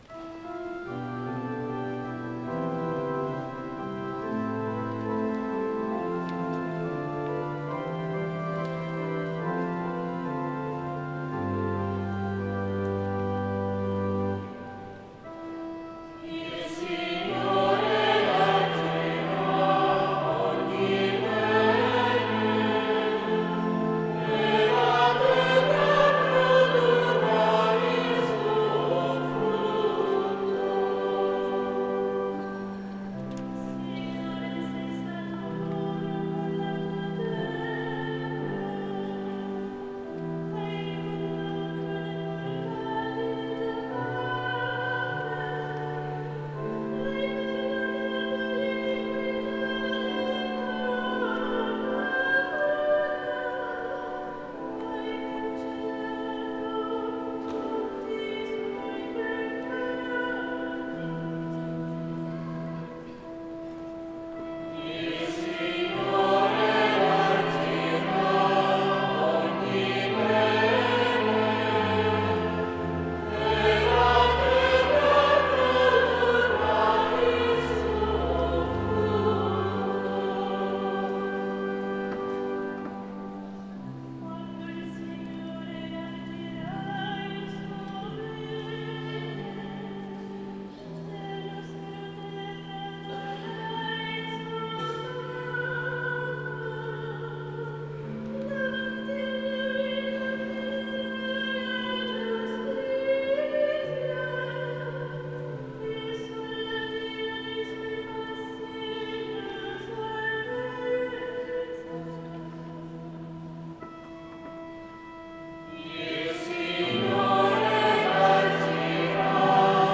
Gallery >> Audio >> Audio2017 >> Rassegna Corali Diocesane >> 01-RassCorali 26Nov2017 Altofonte